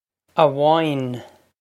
amháin a-woyne
Pronunciation for how to say
This is an approximate phonetic pronunciation of the phrase.